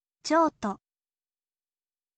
jouto